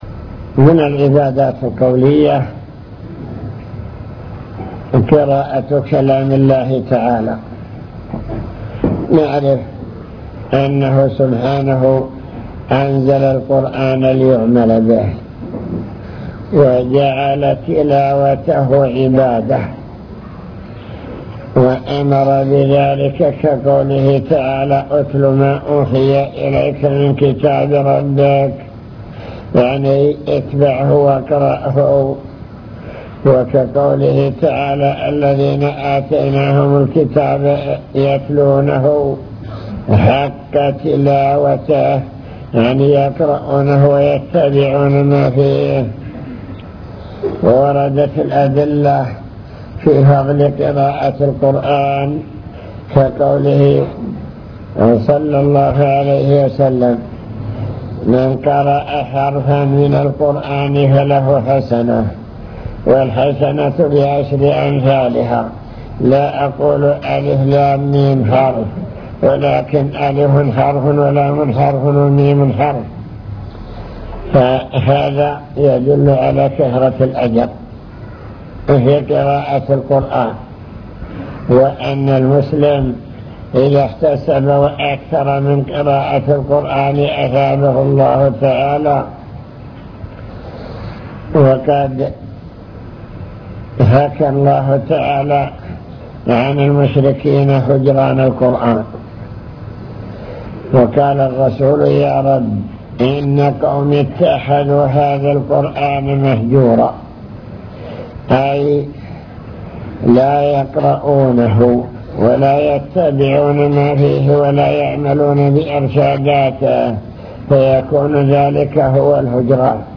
المكتبة الصوتية  تسجيلات - محاضرات ودروس  نوافل العبادات وأنواعها العبادات القولية